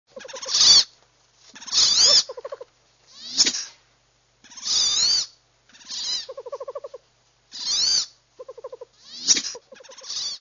Karolinka - Aix Sponsa
głosy